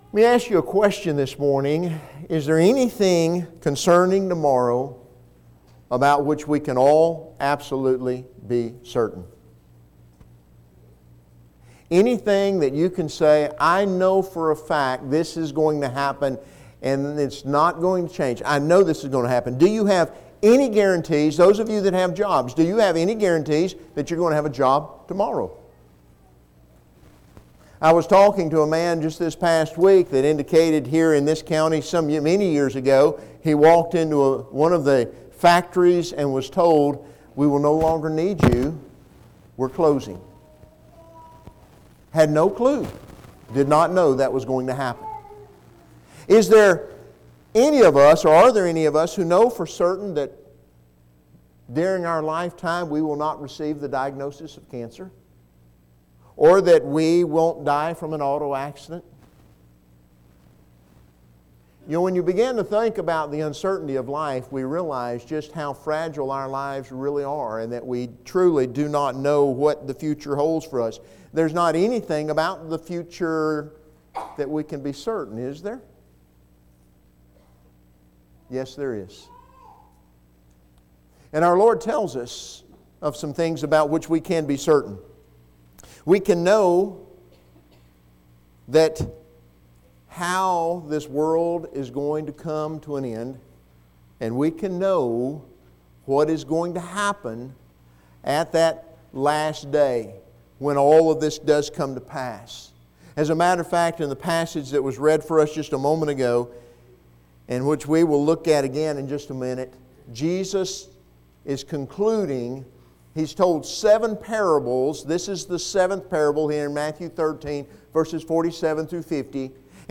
Philippians 2:19-30 Service Type: Sunday Morning